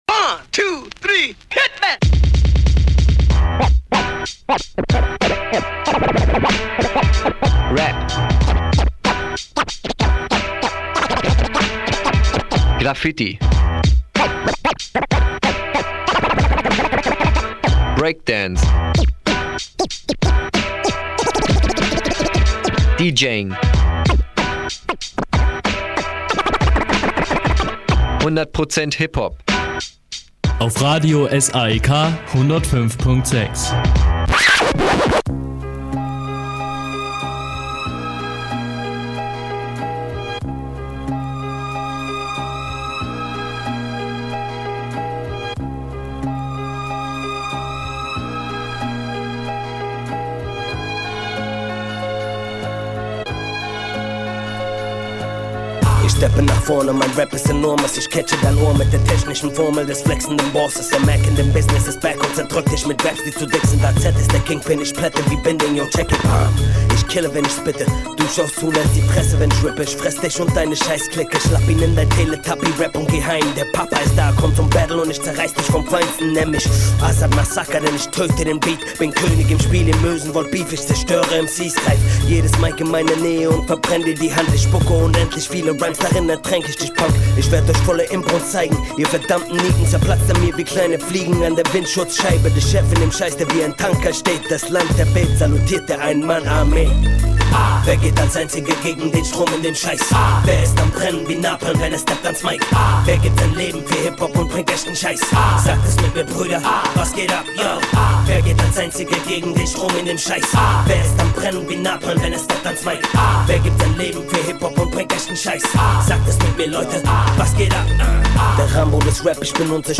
Inhalte: Newz, VA Tips, Musik